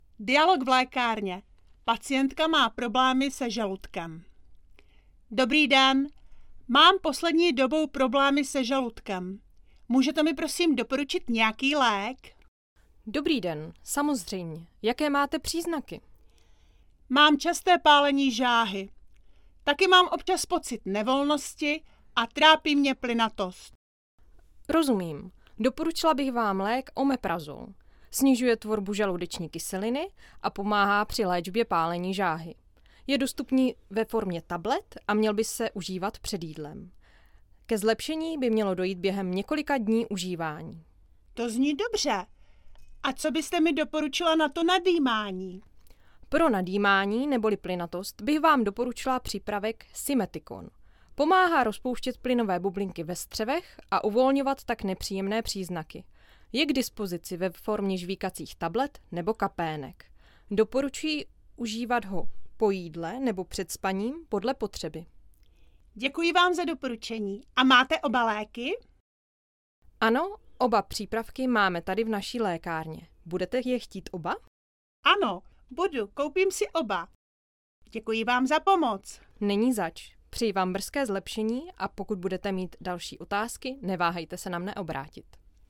dialog02_zaludek.mp3